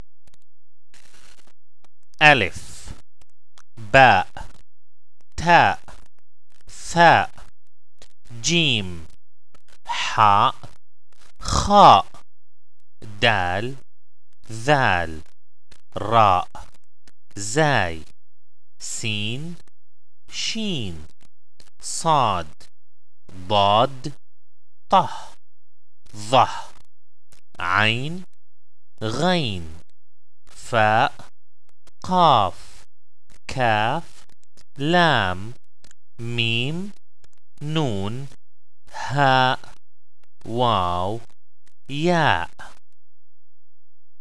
阿拉伯語之英語拼寫及發音 Arabic Spelling & Pronunciation Written in English
Pronunciation Tips